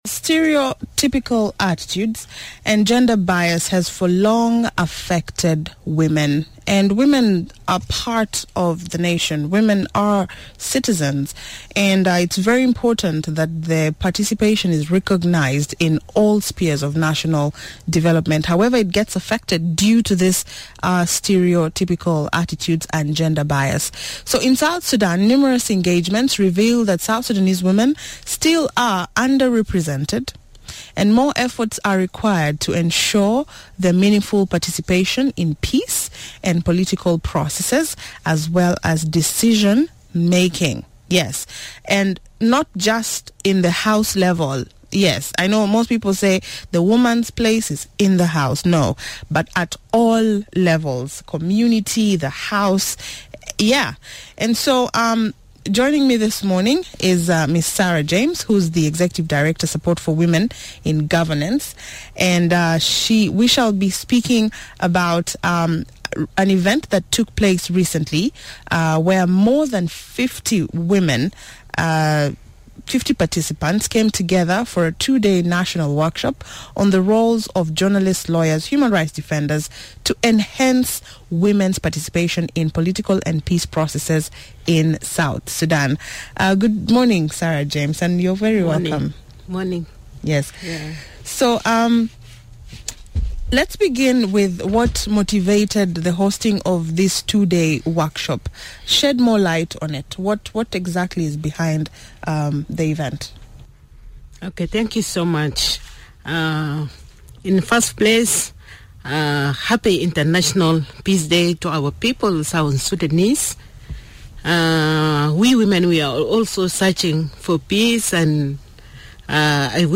This morning, Miraya breakfast featured the stakeholders who shade more light on their efforts towards women empowerment.